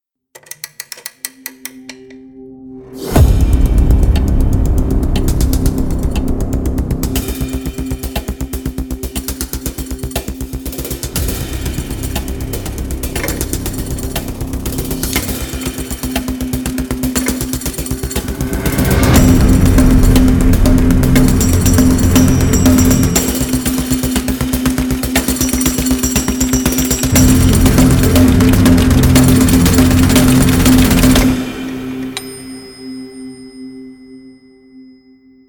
Strezov Sampling Lightning X3M是一款有机和电子高频打击乐器，基于Strezov Sampling的著名的X3M打击乐引擎，专为KONTAKT设计。
从钟表、沙锤、铅笔、风琴、电子音到水晶玻璃，Lightning X3M为您提供了一个丰富多样的高频打击乐库。
该库具有多个动态级别（从非常柔和的ppp到爆破ffff），并具有多个循环（这意味着每个乐器都有独特的样本，每次按下某个调时都会更改）。